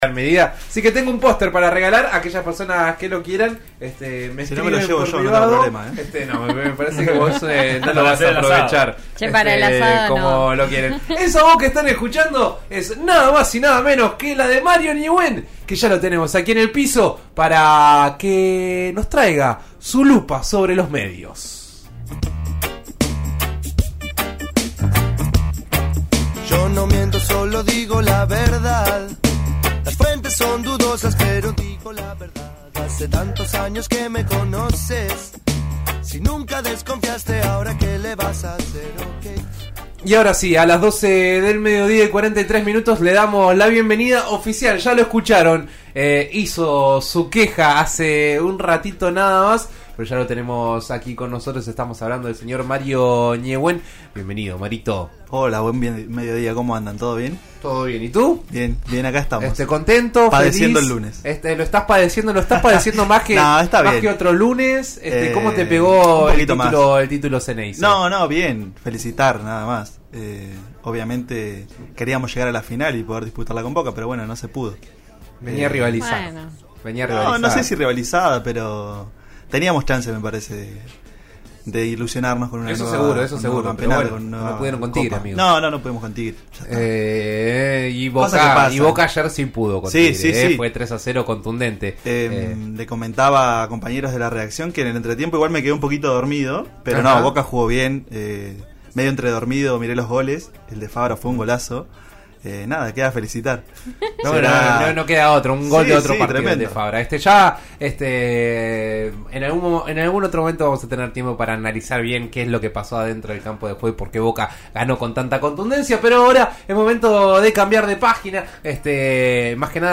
En La Lupa conversamos sobre los temblores que se registran en la pequeña localidad neuquina y su posible relación con el fracking.